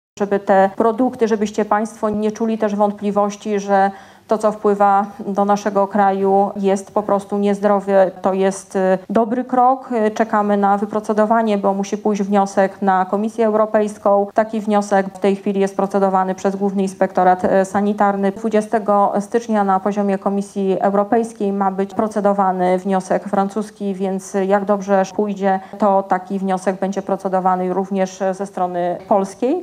Zakaz importu do Polski produktów rolno-spożywczych zawierających pestycydy chce wprowadzić Ministerstwo Rolnictwa i Rozwoju Wsi. Poinformowała o tym na spotkaniu z rolnikami w Janowie Podlaskim wiceminister resortu Małgorzata Gromadzka.